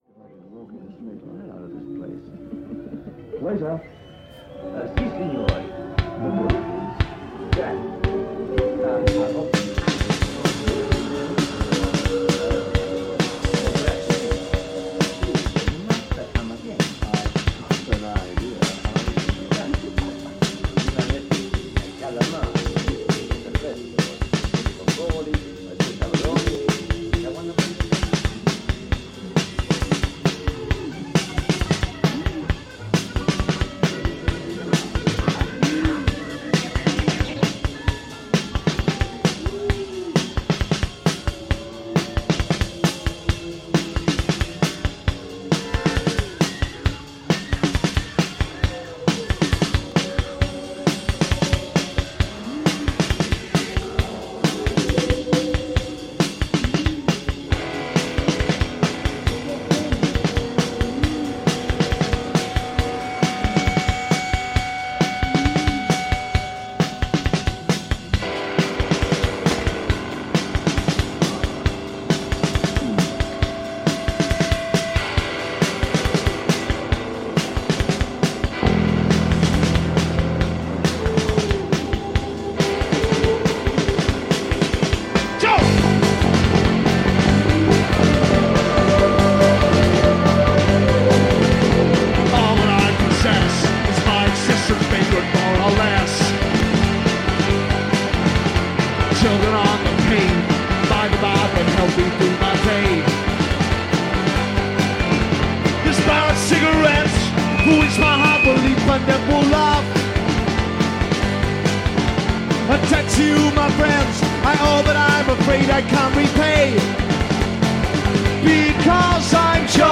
recorded live at Manchester University
A dose of early Madchester to start the week.